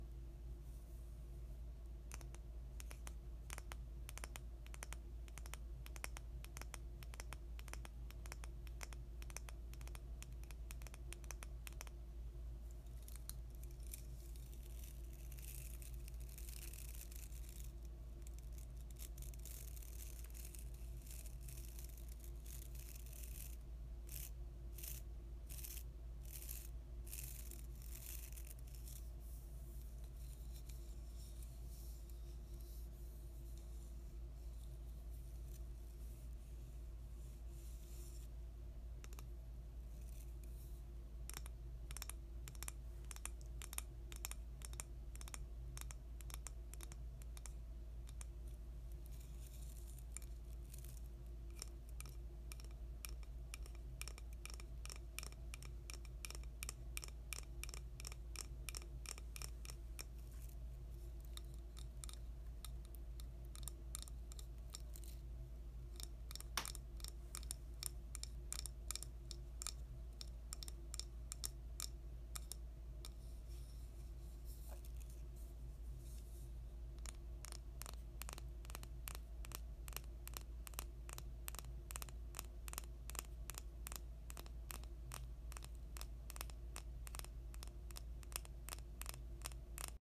音フェチ★ブラシ